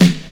• 1990s Original Hip-Hop Steel Snare Drum F Key 312.wav
Royality free snare tuned to the F note. Loudest frequency: 921Hz